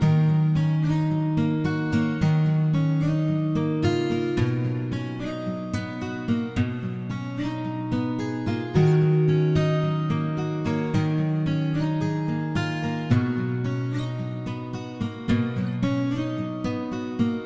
Tag: 110 bpm Trap Loops Guitar Acoustic Loops 2.94 MB wav Key : D Audition